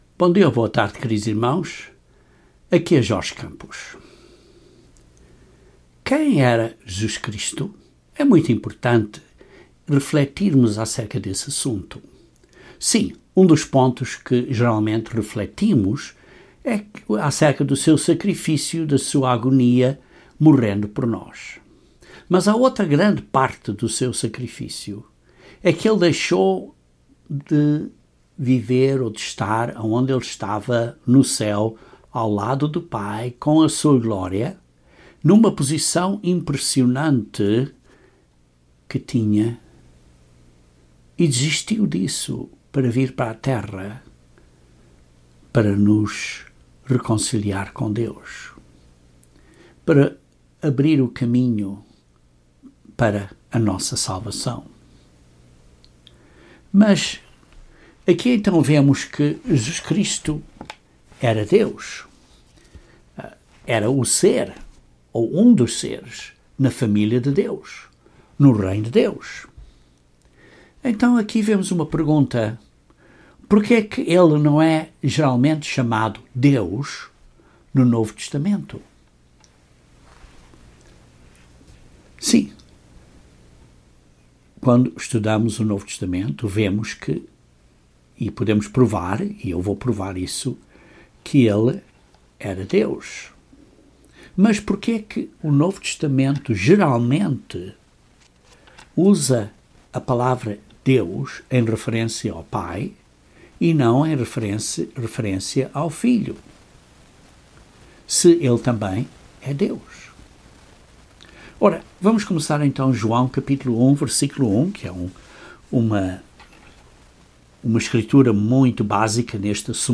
Este sermão demonstra que Jesus é identificado por Deus no Novo Testamento e dá sete razões porque o Novo Testamento geralmente usa Deus em referência ao Pai, e Senhor em referência a Jesus.